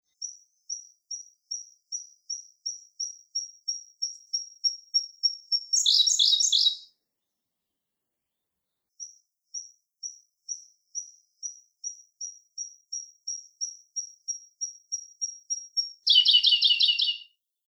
「日本の鳥百科」コルリの紹介です（鳴き声あり）。